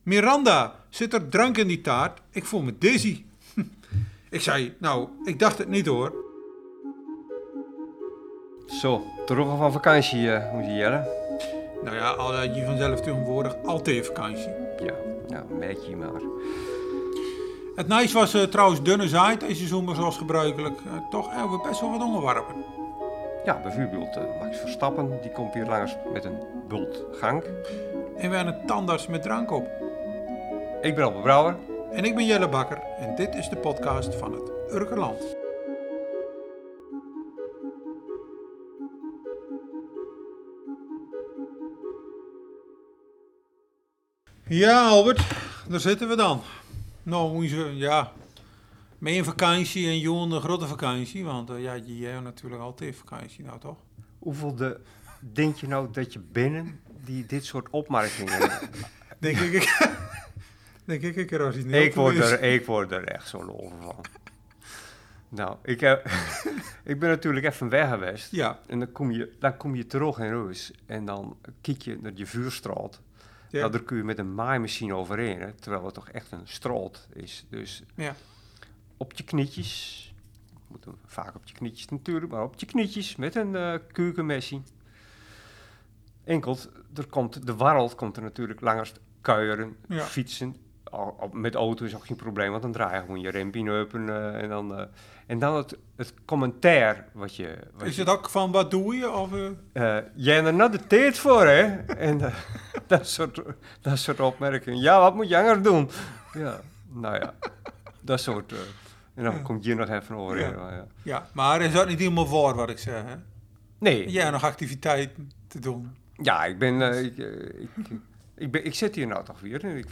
De mannen praten elkaar bij over de afgelopen vakantieweken en over hoe het was om weer terug te keren op Urk. Het eerste dat opviel was dat de Singel nu weer geopend is voor verkeer.